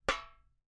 sfx_walk_metal_1.mp3